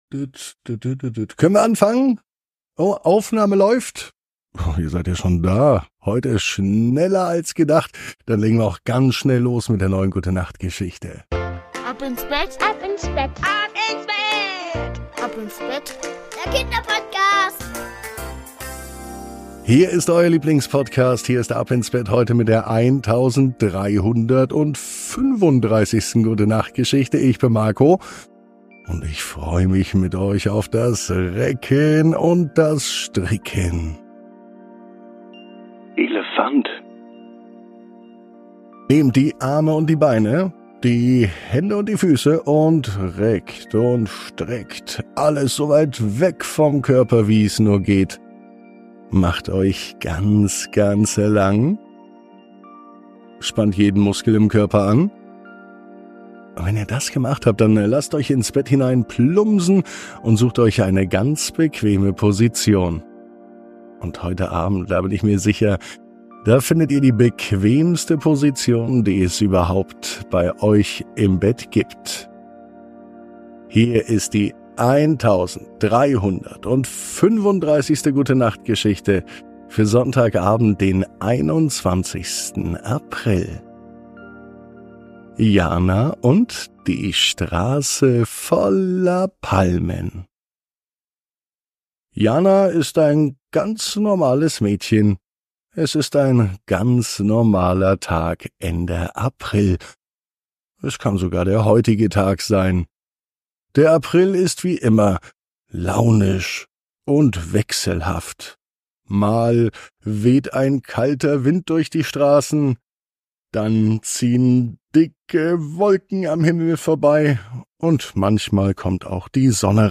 Die Gute Nacht Geschichte für Sonntag